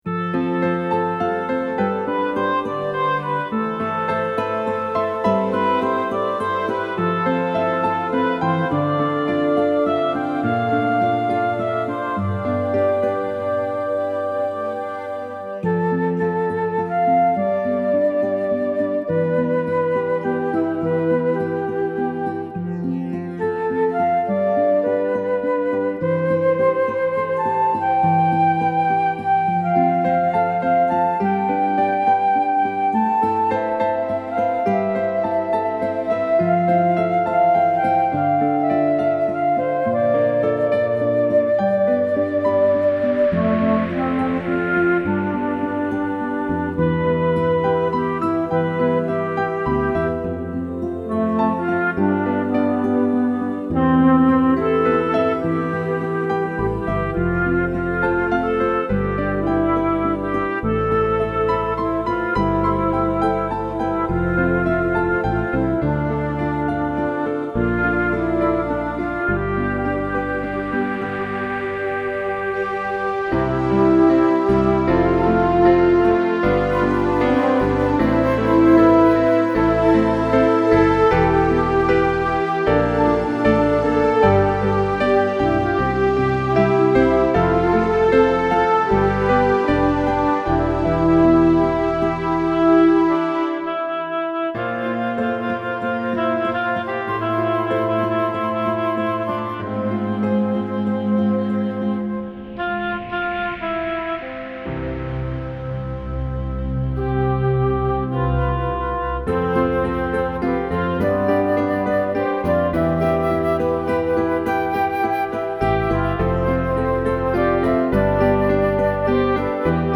Initially, I worked with an arranger to create these lush arrangements.
4-how-we-dont-care-instrumental.mp3